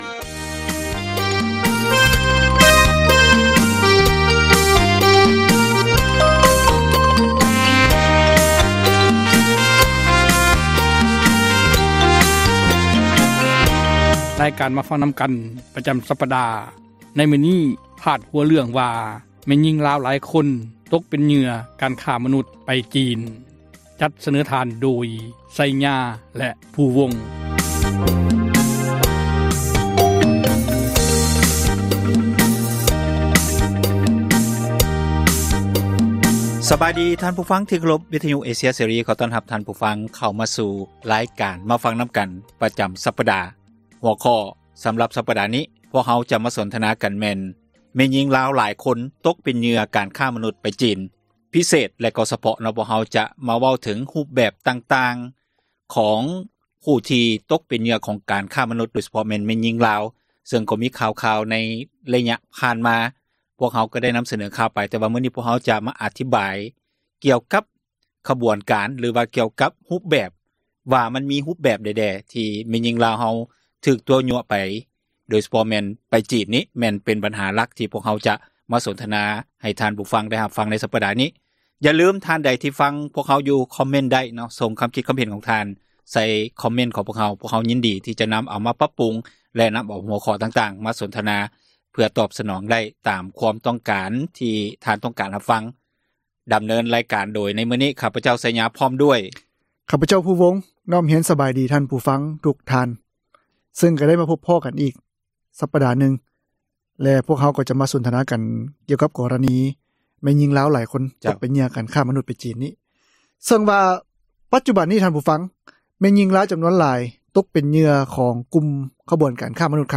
"ມາຟັງນຳກັນ" ແມ່ນຣາຍການສົນທະນາ ບັນຫາສັງຄົມ ທີ່ຕ້ອງການ ພາກສ່ວນກ່ຽວຂ້ອງ ເອົາໃຈໃສ່ແກ້ໄຂ,